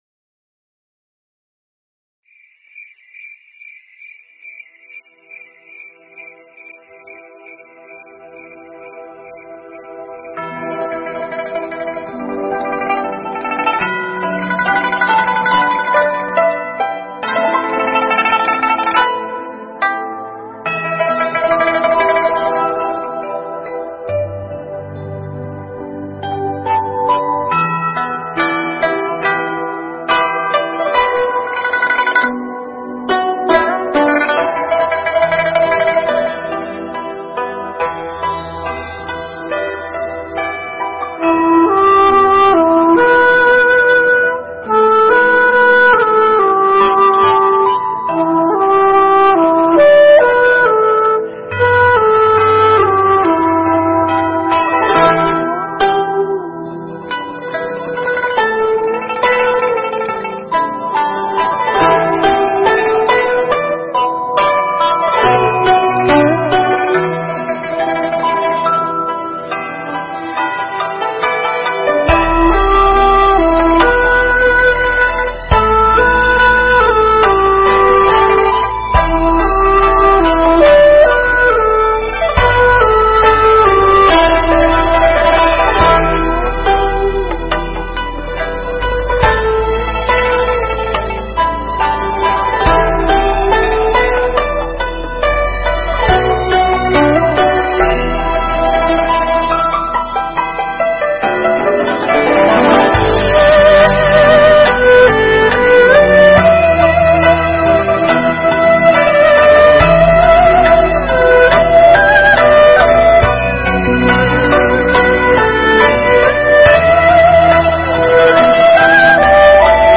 thuyết giảng tại Tu Viện Tây Thiên, Canada